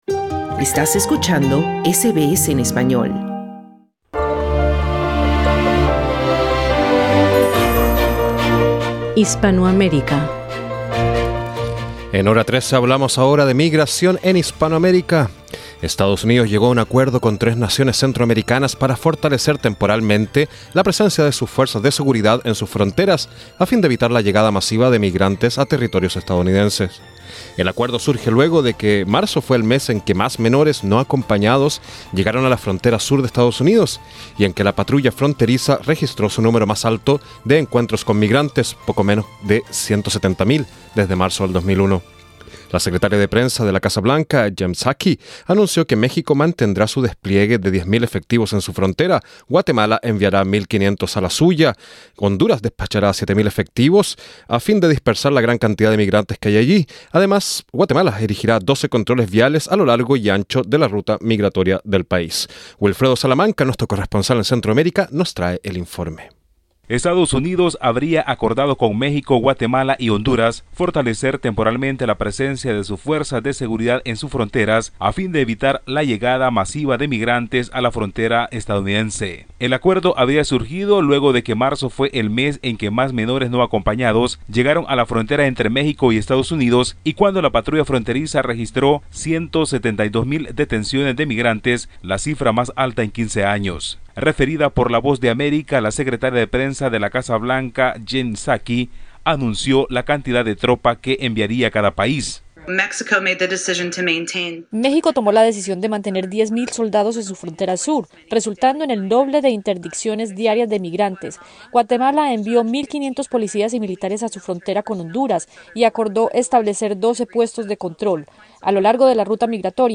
Escucha el informe de nuestro corresponsal en Centroamérica